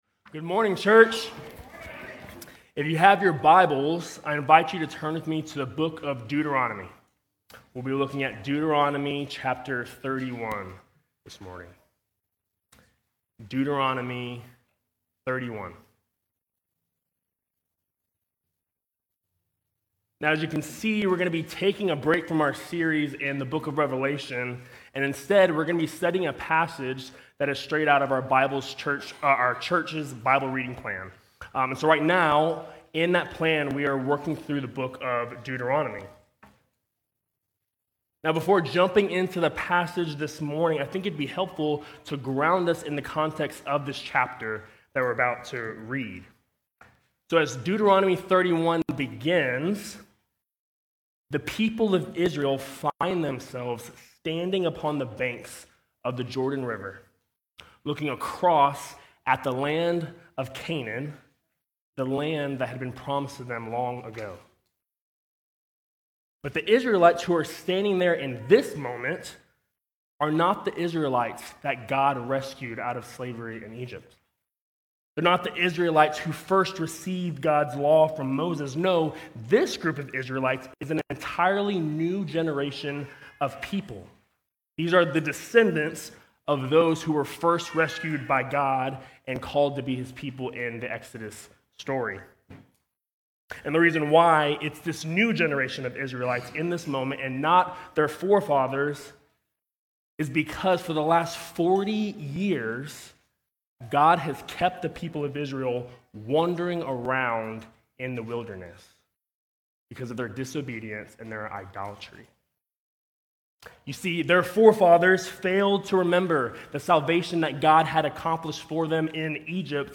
Sermons | St. Rose Community Church